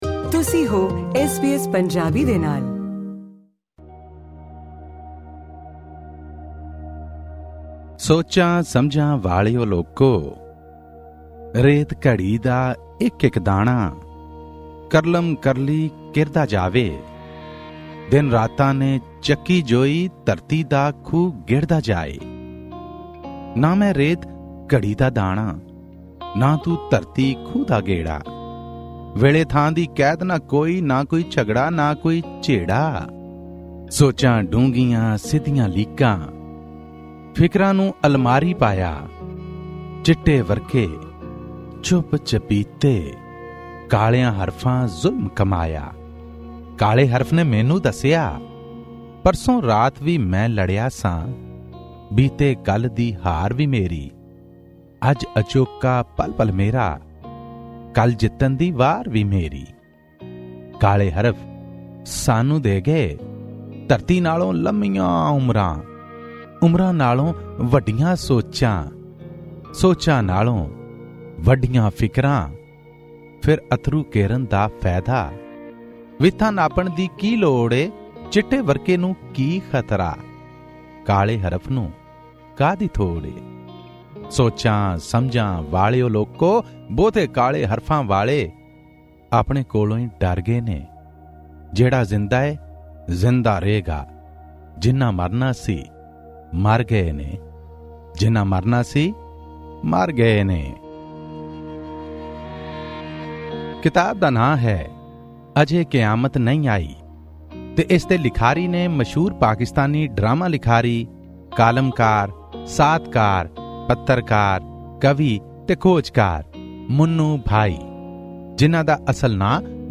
Click on the audio player to listen to this review in Punjabi.